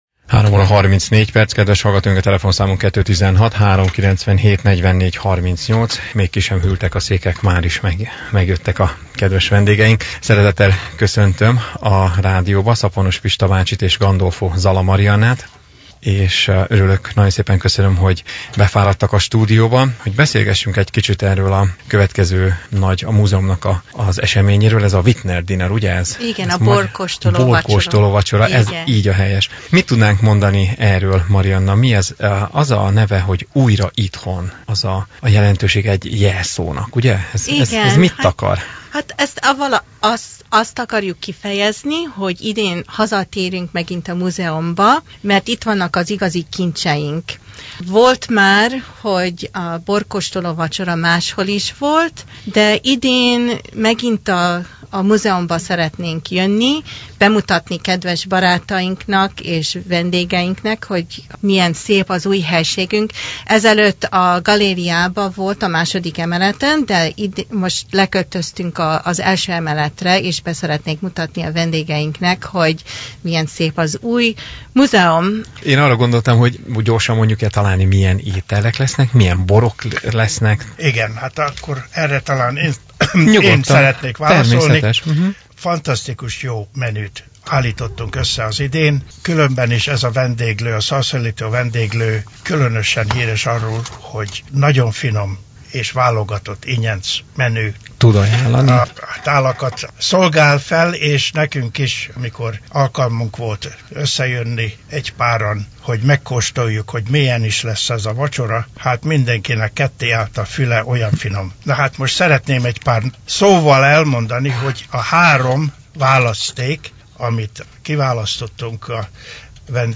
A Clevelandi Magyar Múzeum borkóstoló vacsorára hívja támogatóit - Interjú – Bocskai Rádió